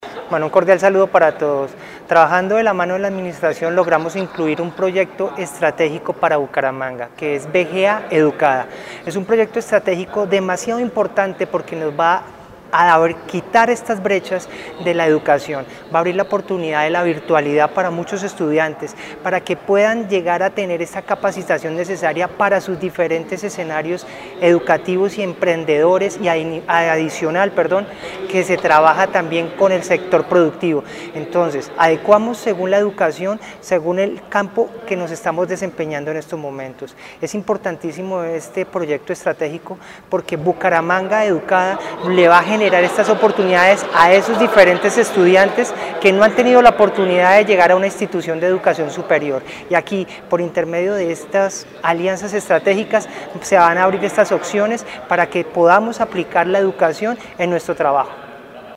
Luis Eduardo Ávila, ponente del Proyecto de Acuerdo del Plan de Desarrollo 2020-2023